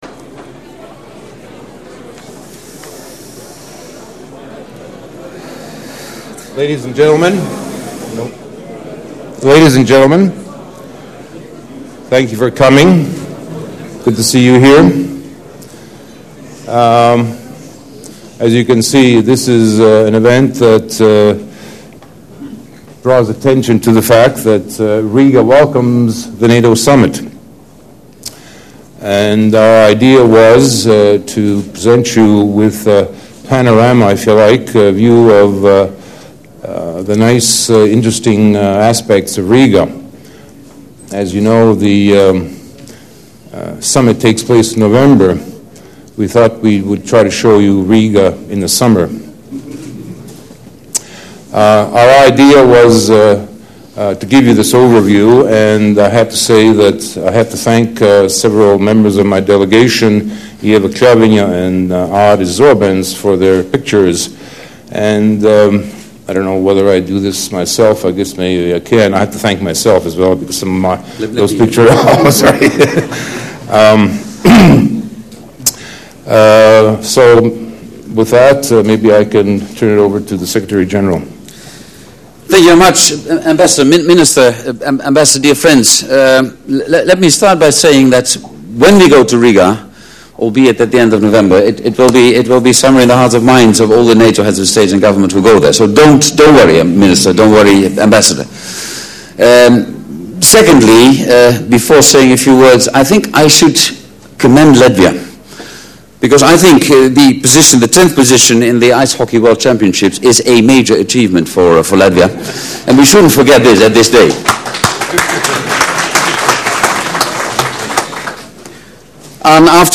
The logo of the Summit meeting of NATO Heads of State and Government, which is to be held in the Latvian capital Riga on 28 and 29 November, was presented at a special ceremony at NATO HQ, accompanied by a jazz concert and photo exhibition.
Audio Statements by the Permanent Representative of Latvia to NATO, NATO Secretary General Jaap de Hoop Scheffer and the Latvian Defence Minister at the presentation of the Riga Summit logo, opens new